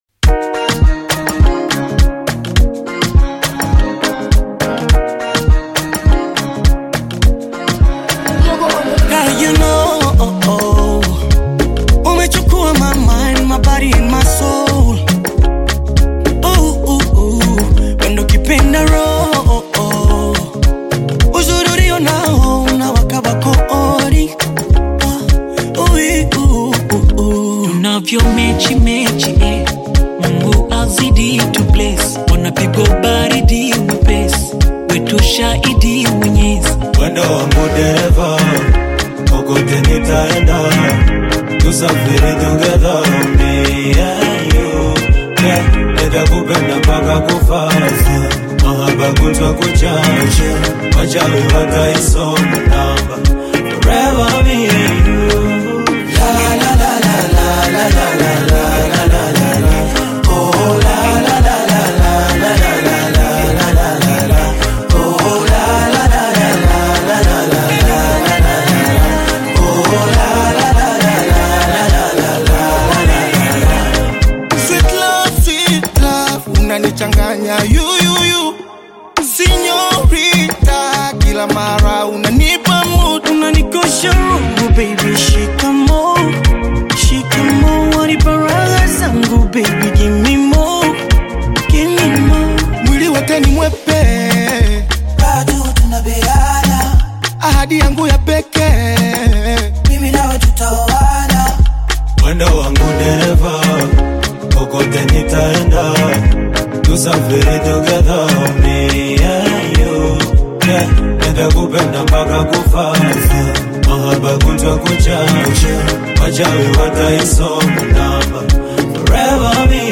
the hottest Bongo Flava artist
African Music